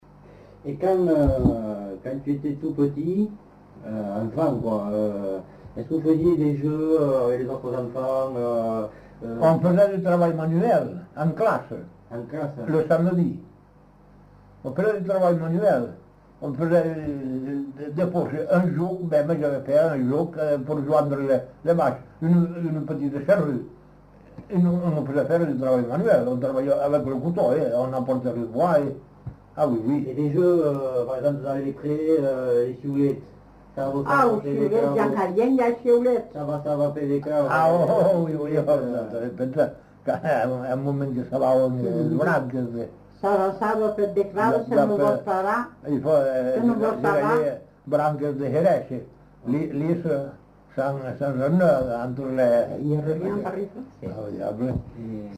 Lieu : Montauban-de-Luchon
Genre : témoignage thématique
Instrument de musique : sifflet végétal